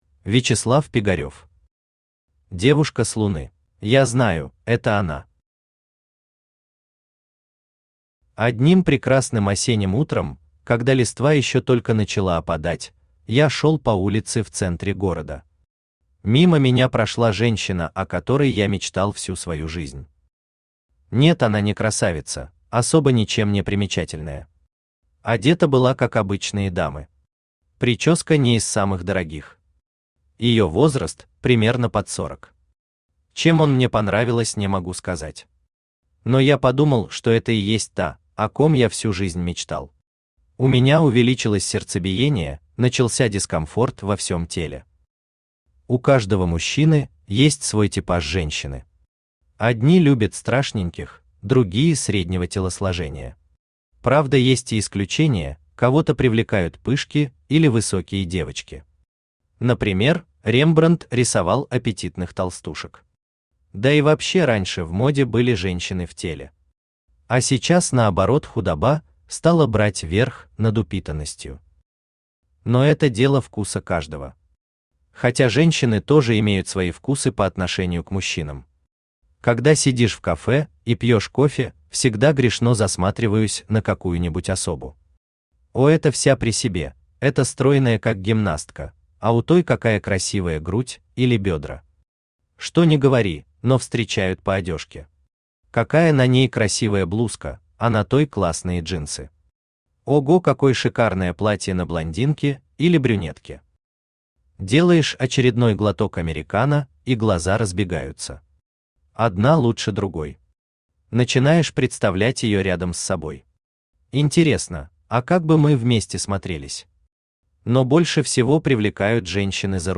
Аудиокнига Девушка с луны | Библиотека аудиокниг
Aудиокнига Девушка с луны Автор Вячеслав Пигарев Читает аудиокнигу Авточтец ЛитРес.